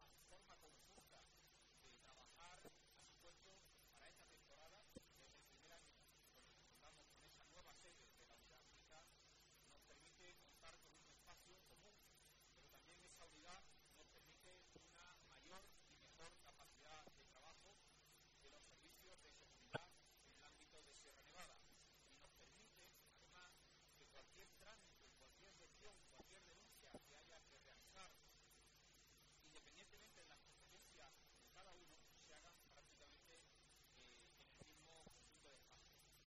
Consejero de la Presidencia, Interior, Diálogo Social y Simplificación Administrativa, Antonio Sanz